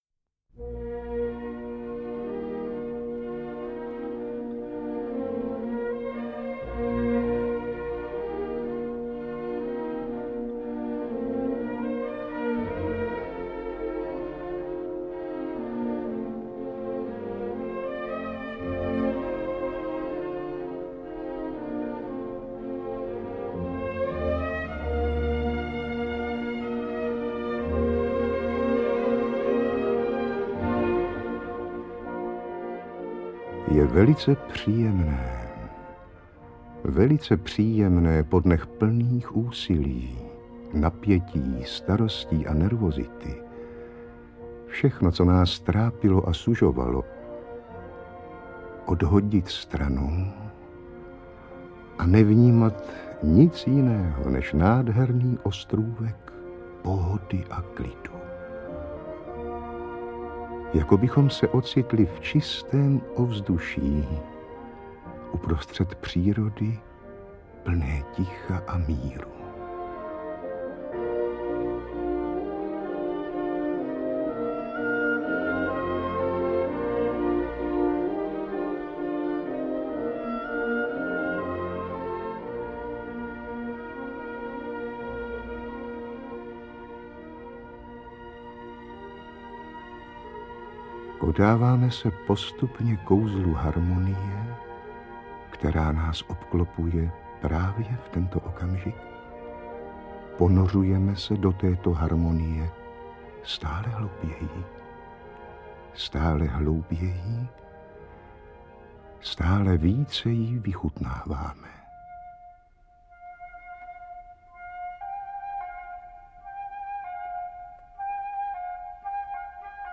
Interpreti:  Eduard Cupák, Miroslav Moravec, Miroslav Moravec
AudioKniha ke stažení, 1 x mp3, délka 54 min., velikost 49,4 MB, česky